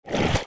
Gaia Ambient Submerge Up.mp3